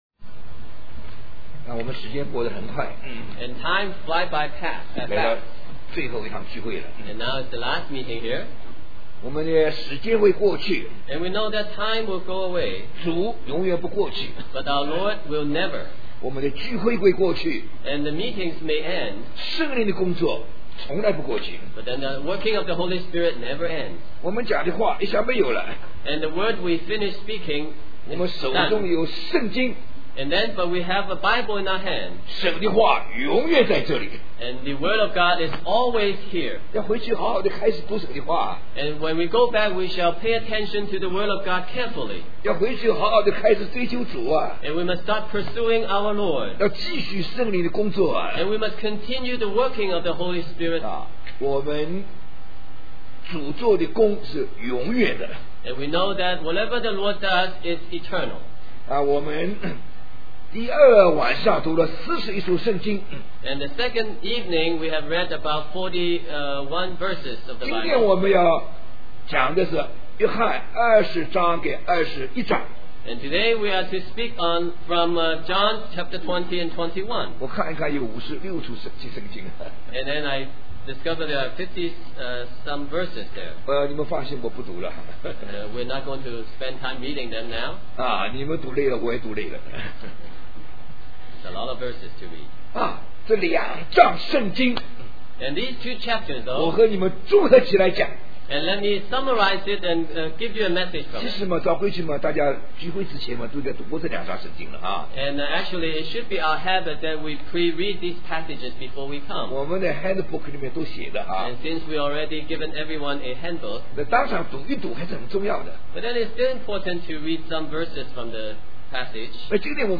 In this sermon, the speaker emphasizes the eternal nature of God and the working of the Holy Spirit.